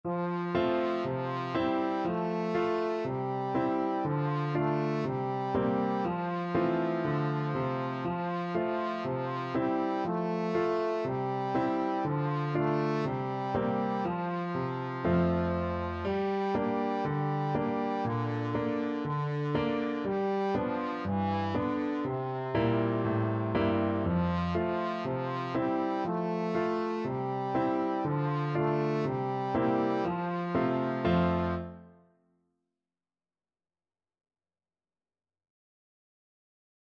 Traditional Music of unknown author.
4/4 (View more 4/4 Music)
Allegro (View more music marked Allegro)
C4-A4